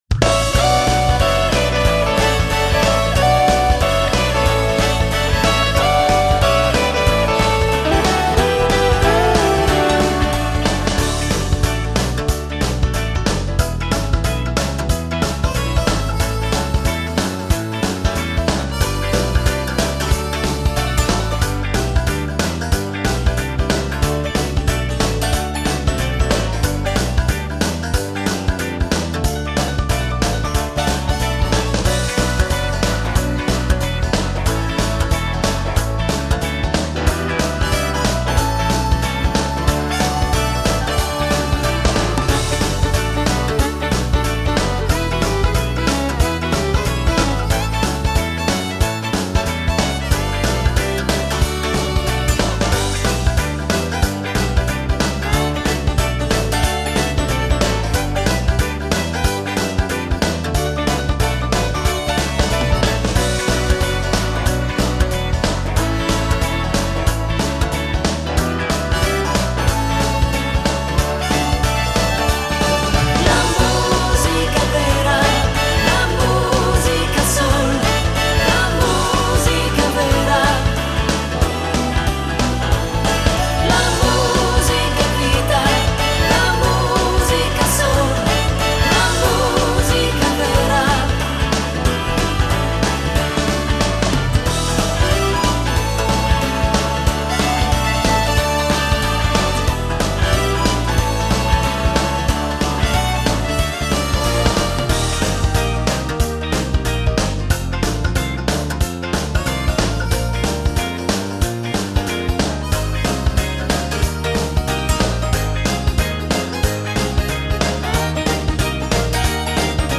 Genere: Moderato rock
Scarica la Base Mp3 (3,70 MB)